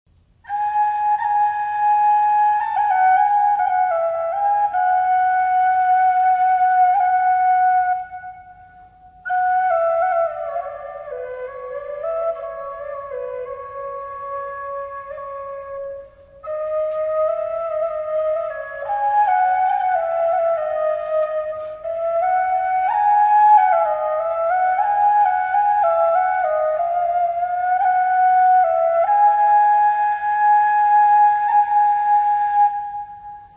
FLUTE A BEC
Flûte "alto"
ALLA FRANCESCA